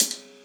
5 Foyer Rimshot.wav